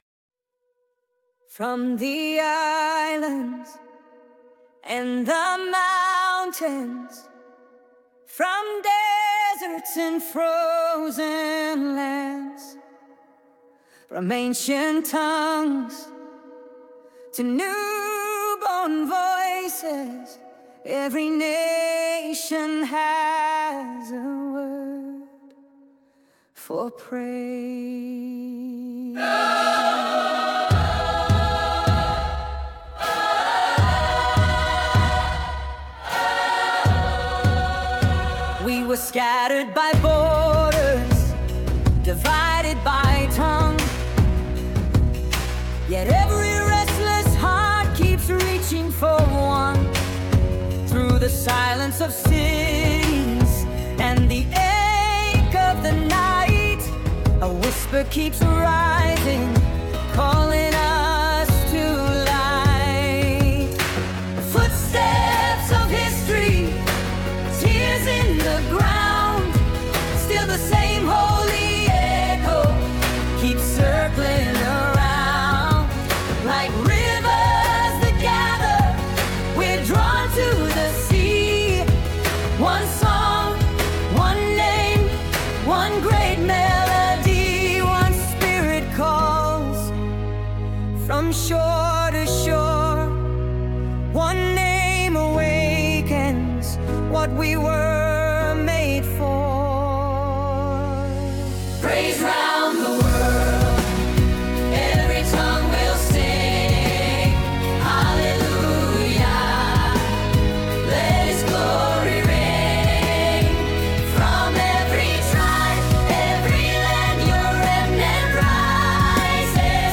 Original worship music including
• [ Practice Track – Higher Key ]